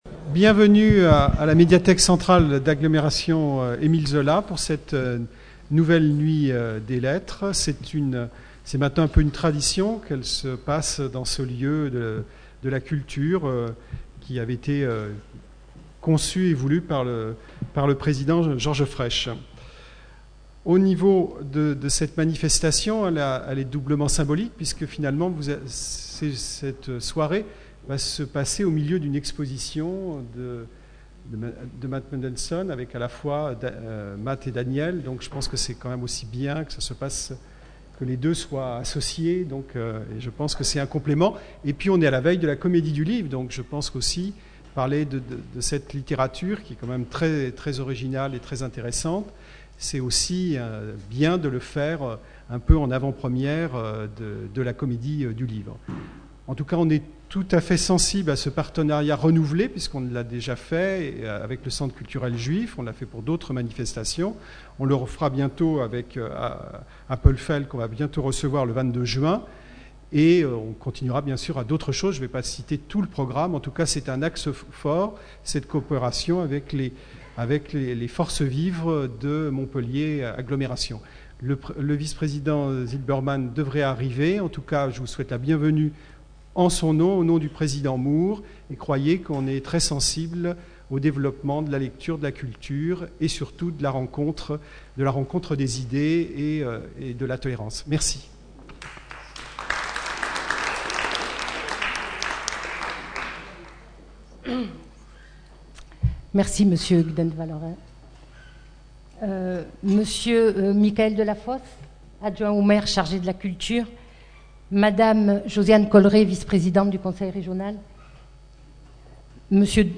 Rencontre littéraire